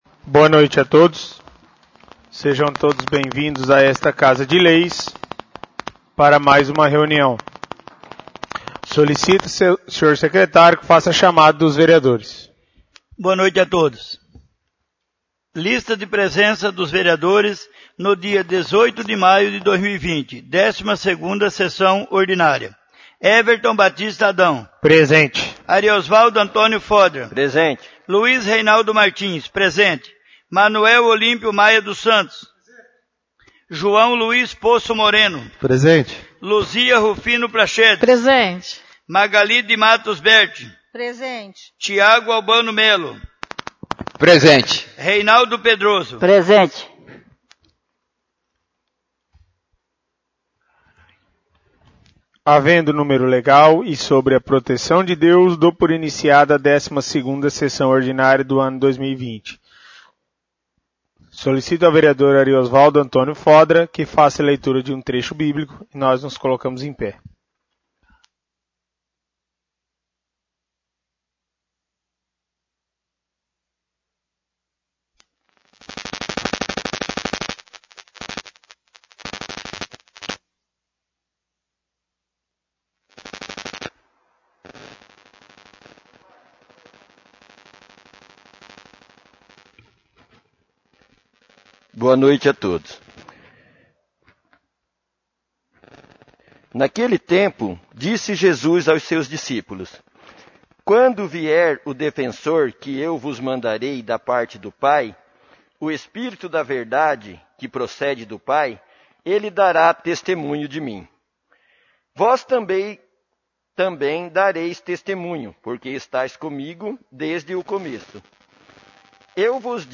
12° sessão ordinária